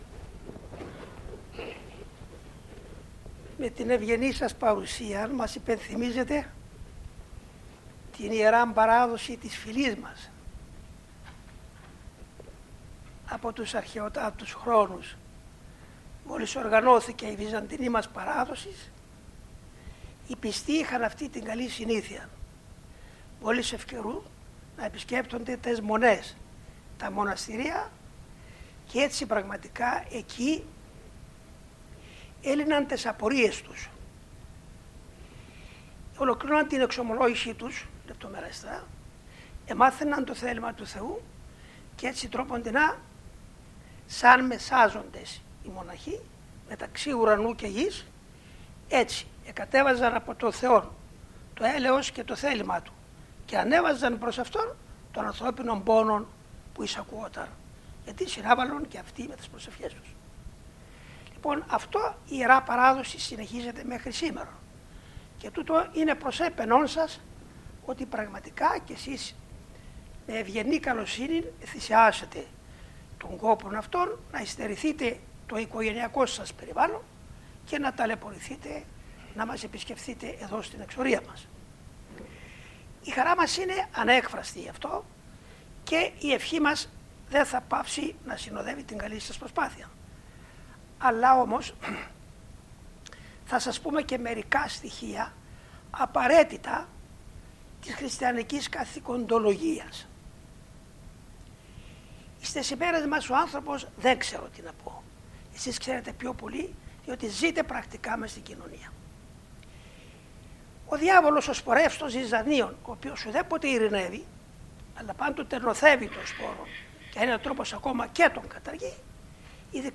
25/3/1996 Κυριακή των Βαΐων - Ομιλία σε λαϊκούς